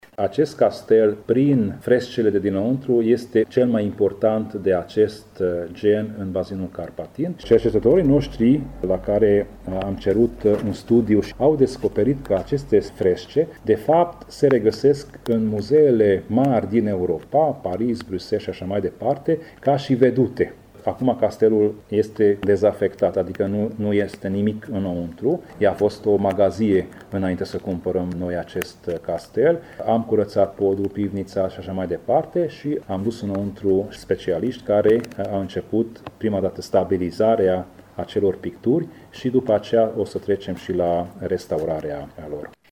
Conform planurilor, în clădirea castelului va fi amenajat un muzeu dedicat calului, o sală de conferinţe, spaţii de cazare, o cramă şi un restaurant. Tamas Sandor, preşedintele Consiliului Judeţean Covasna: